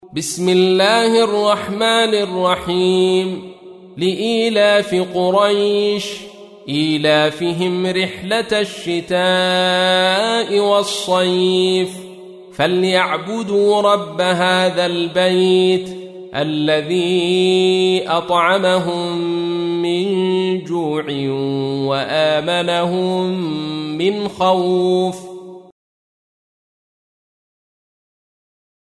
تحميل : 106. سورة قريش / القارئ عبد الرشيد صوفي / القرآن الكريم / موقع يا حسين